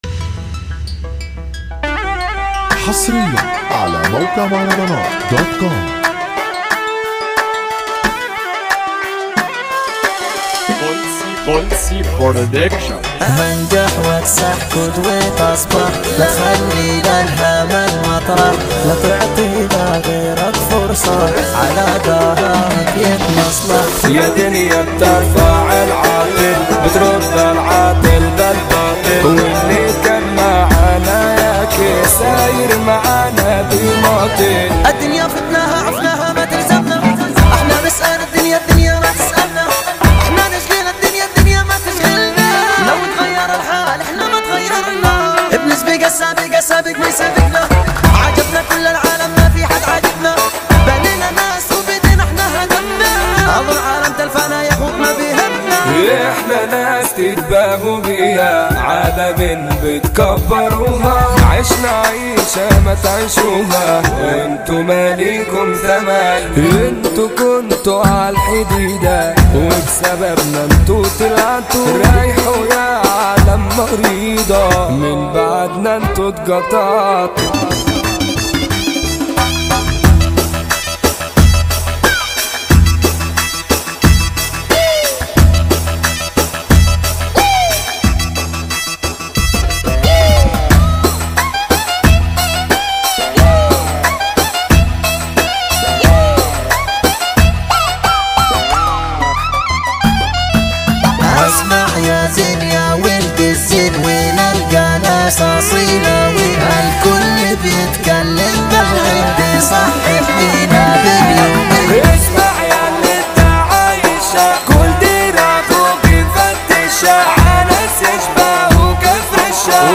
اغاني شعبي ومهرجانات